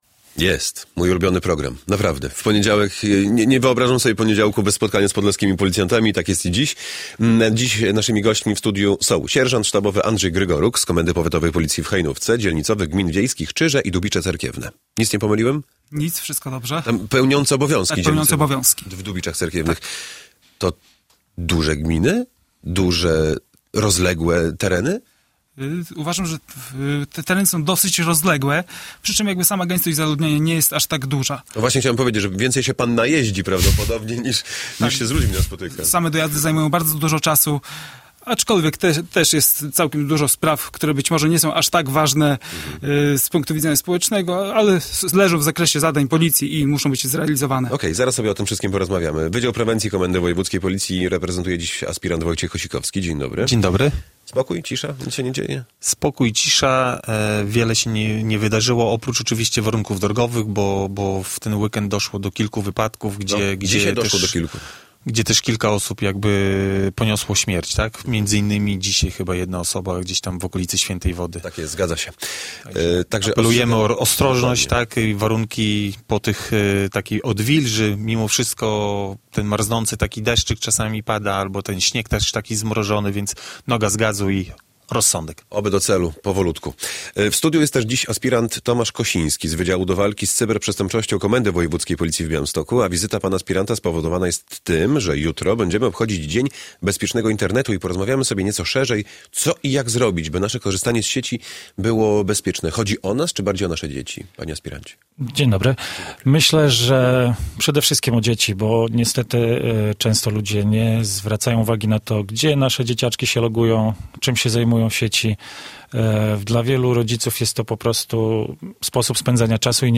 Radio Białystok | Gość | 10:10 z dzielnicowym -